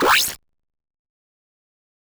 Charge2.wav